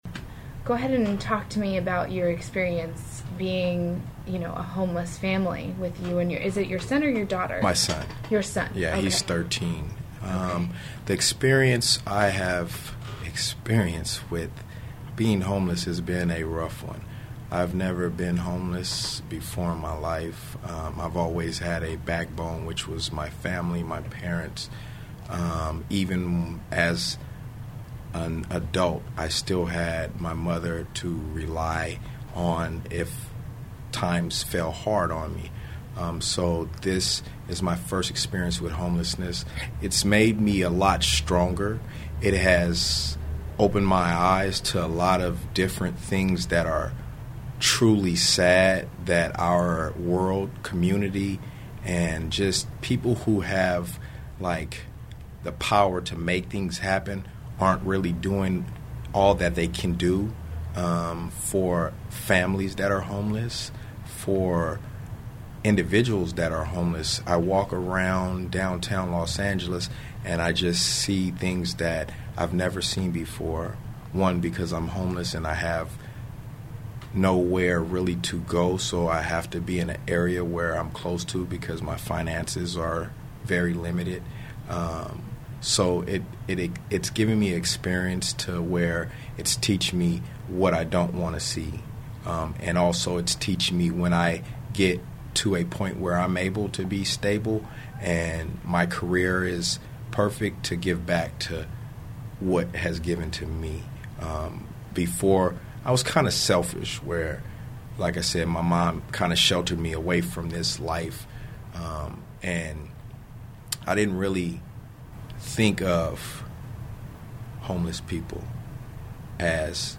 An Interview with a Homeless Single Los Angeles Father (extended version) | USC Annenberg Radio News
hostinterviewextended.mp3